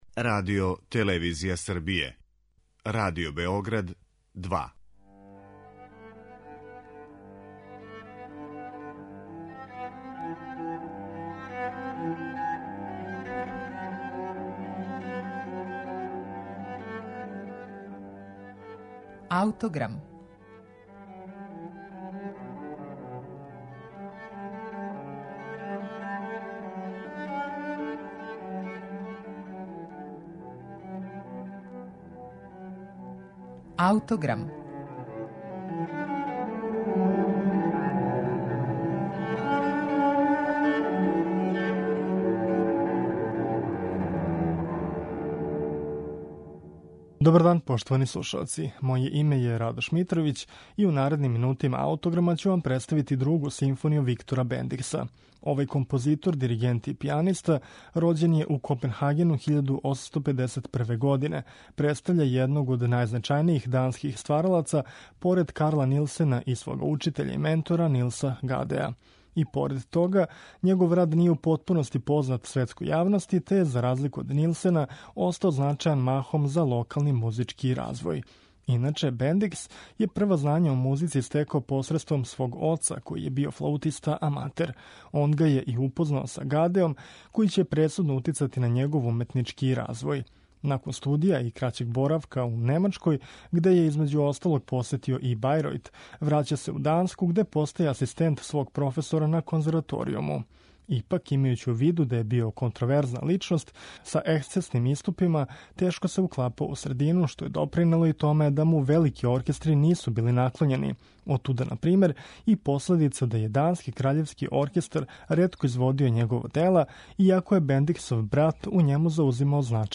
Венсан Д'енди: Други гудачки квартет
Други квартет Венсана Д'Eндија ћемо слушати у извођењу квартет Јоахим.